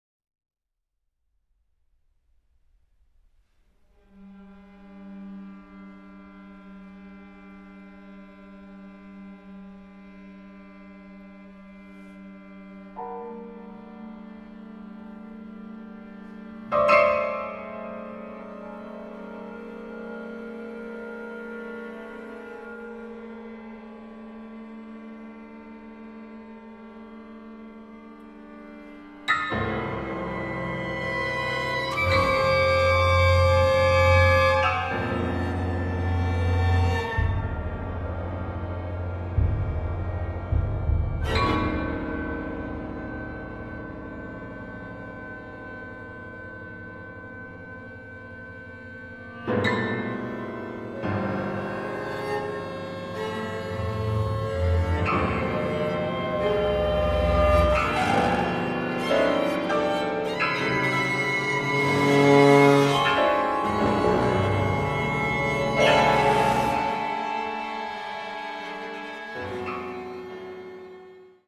one act opera
Westdeutscher Rundfunk, Cologne, Germany